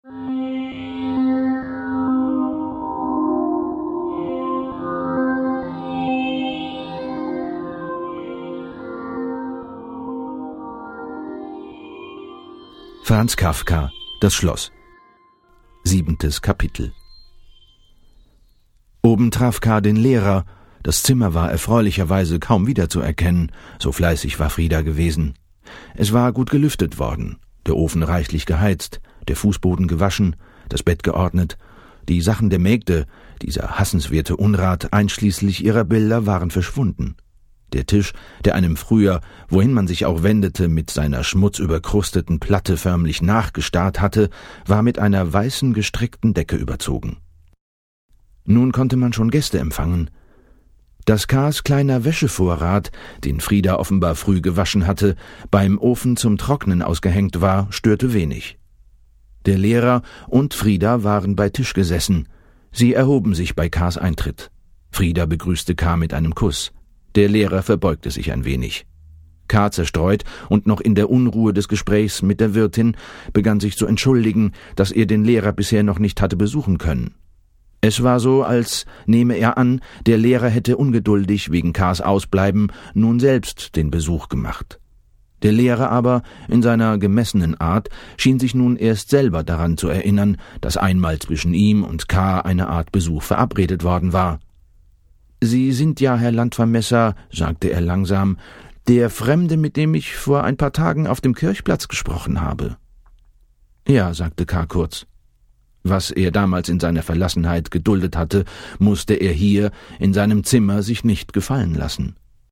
Sprechprobe: Sonstiges (Muttersprache):
Voice Talent for more than 18 years- German dubbing voice Javier Bardem, friendly, warm voice